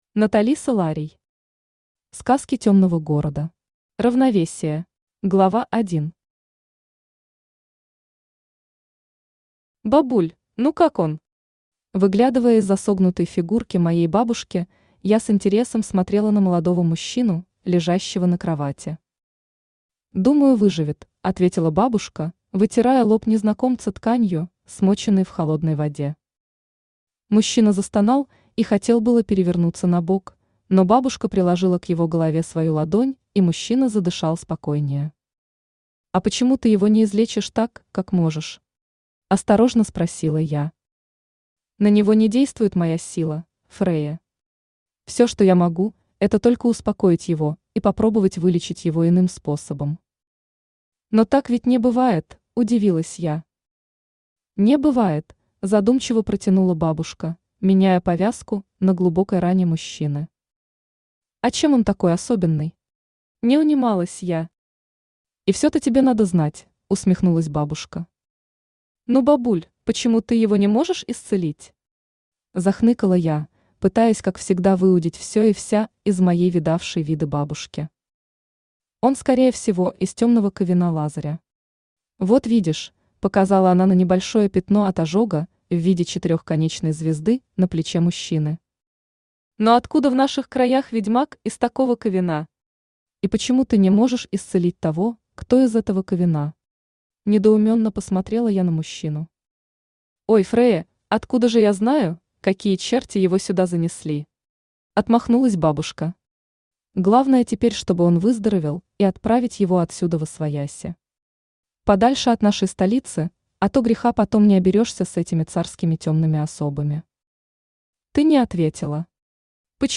Аудиокнига Сказки темного города. Равновесие | Библиотека аудиокниг
Равновесие Автор Наталиса Ларий Читает аудиокнигу Авточтец ЛитРес.